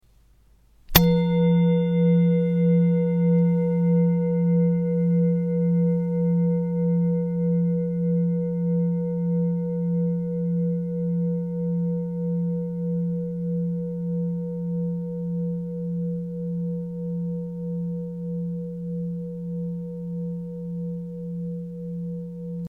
3 Klangschalen für die Klangmassage
Sehr gute Klangqualität - sehr lange anhaltender Klang
Grundton 172,46 hz
1. Oberton 484,61 hz